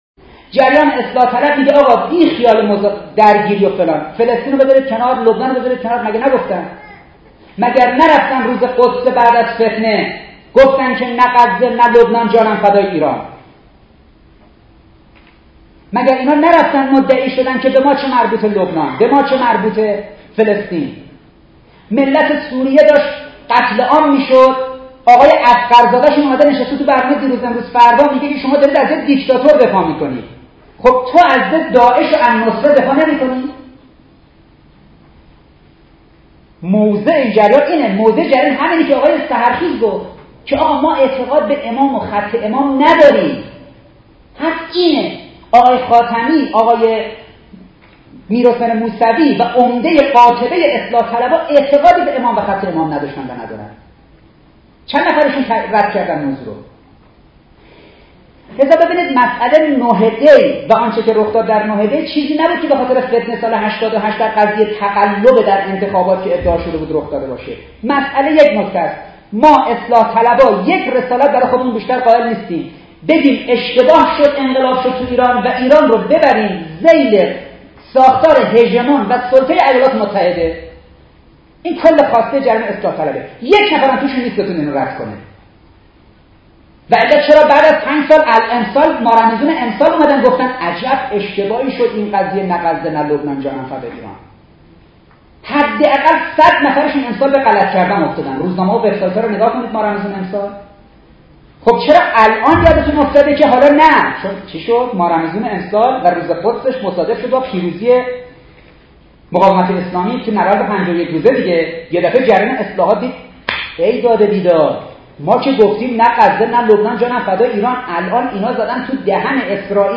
کلیپ صوتی جنجالی و انقلابی و پرشور از استاد عباسی : اصلاح طلبان هیچ اعتقادی به امام و خط امام نداشتند و ندارند/هیچ اصلاح طلبی عقیده ندارد که اسلام می تواند /اصلاح طلبی یعنی روحانیت منهای اسلام
• انقلابی, جنجالی, خط امام, اصلاح طلبان, پرشور, دکتر حسن عباسی